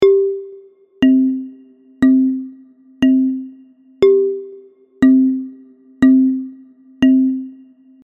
Im folgenden Audiobeispiel hören Sie zwei Takte im 4/4-Takt, bei denen der erste Schlag leicht hervorgehoben ist.
Audiobeispiel_Viervierteltakt.mp3